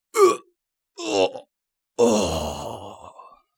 ZS死亡1.wav
ZS死亡1.wav 0:00.00 0:03.58 ZS死亡1.wav WAV · 308 KB · 單聲道 (1ch) 下载文件 本站所有音效均采用 CC0 授权 ，可免费用于商业与个人项目，无需署名。
人声采集素材/男3战士型/ZS死亡1.wav